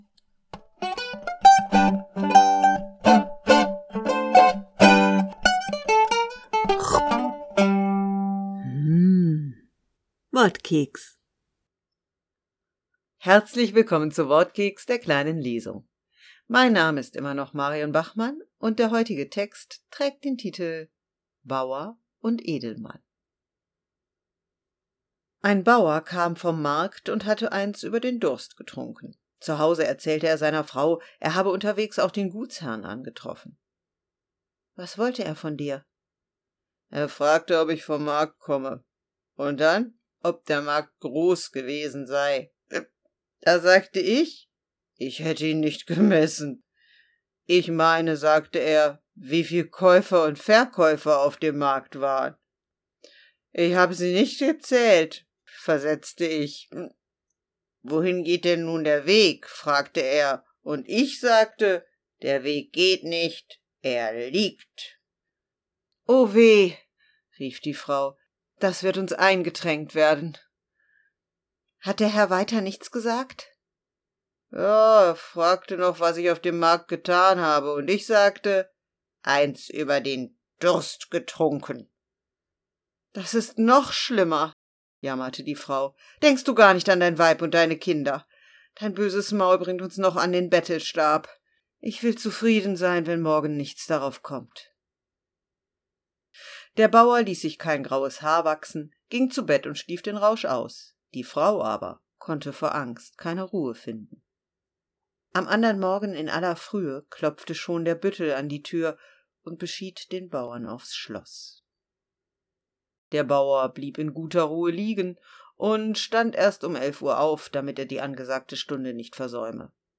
Wortkeks - die kleine Lesung